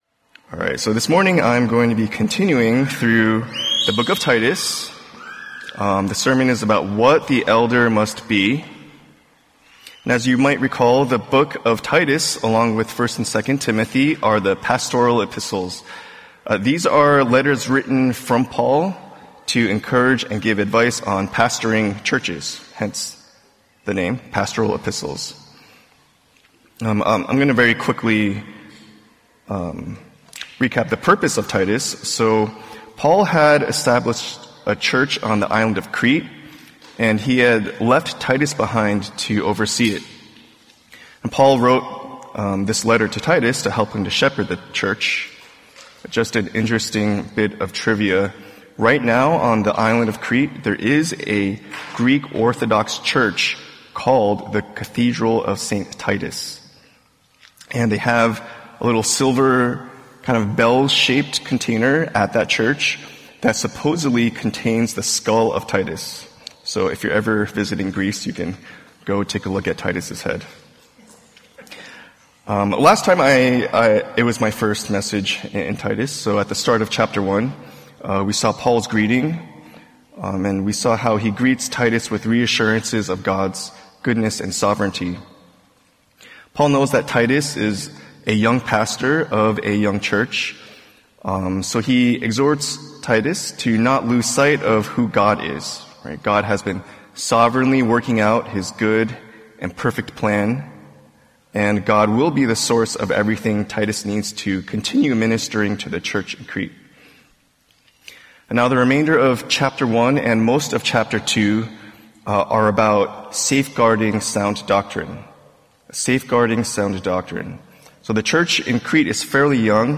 Sunday Service | Service Type | Lighthouse Bible Church Los Angeles | Making Disciples of Christ.